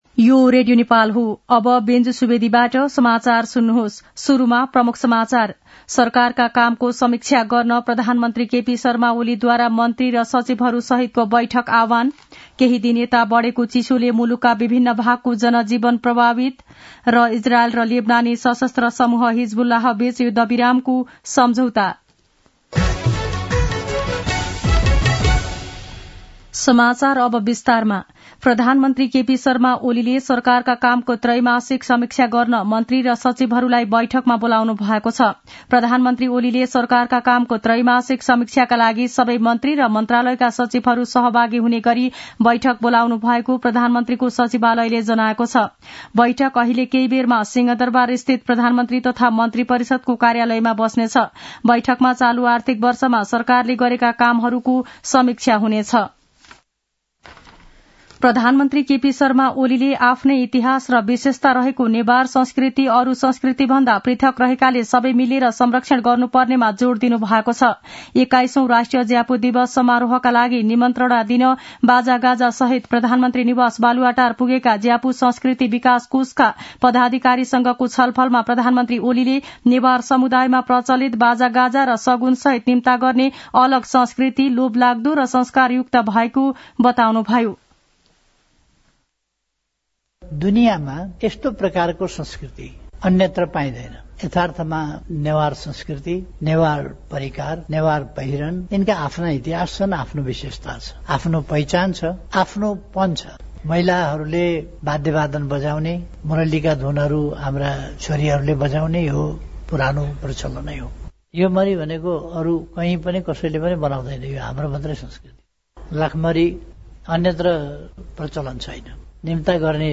दिउँसो ३ बजेको नेपाली समाचार : १३ मंसिर , २०८१
3-pm-nepali-news-1-8.mp3